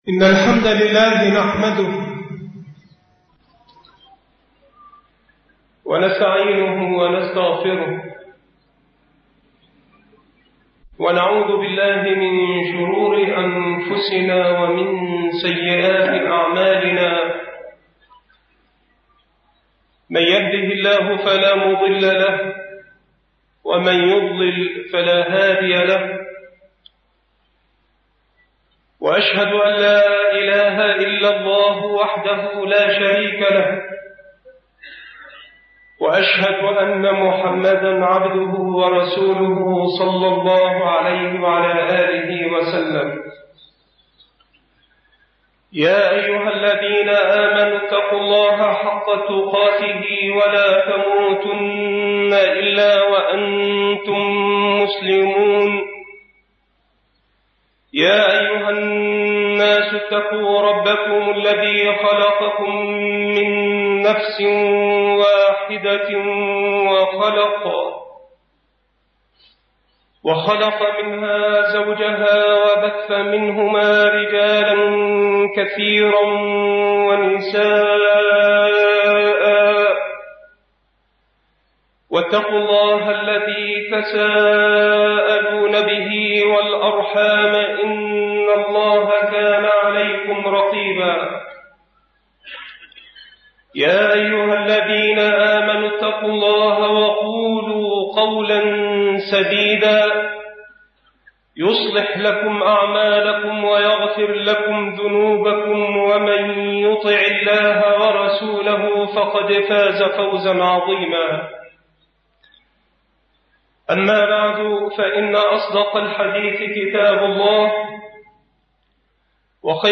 المحاضرة
مكان إلقاء هذه المحاضرة بالمسجد الشرقي - سبك الأحد - أشمون - محافظة المنوفية